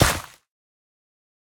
Minecraft Version Minecraft Version latest Latest Release | Latest Snapshot latest / assets / minecraft / sounds / block / suspicious_gravel / step3.ogg Compare With Compare With Latest Release | Latest Snapshot